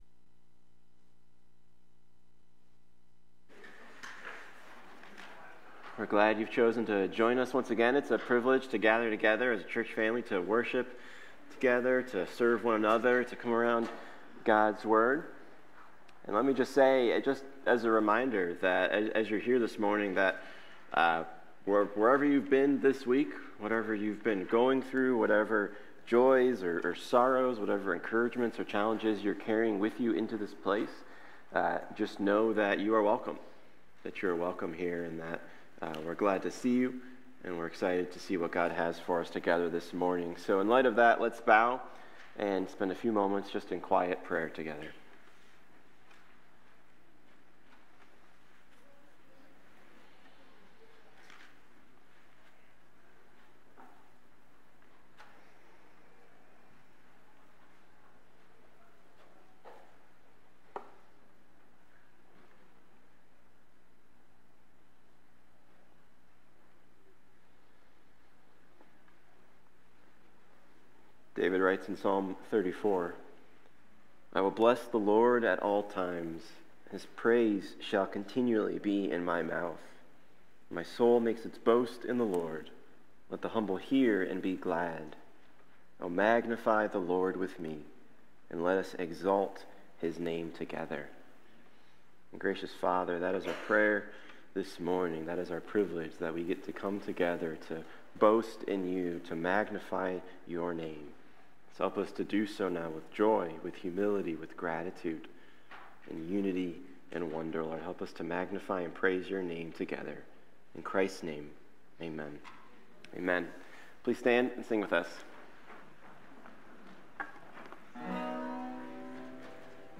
1 Peter 2:11-12 Service Type: Sunday Morning As beloved exiles